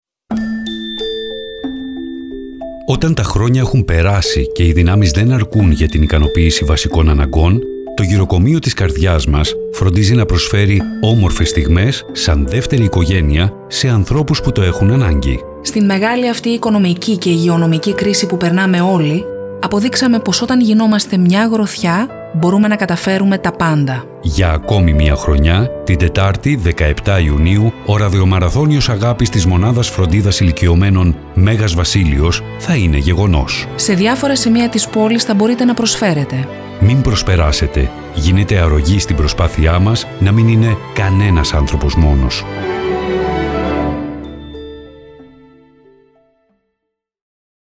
ΡΑΔΙΟΜΑΡΑΘΩΝΙΟΣ-ΣΠΟΤ2-1.mp3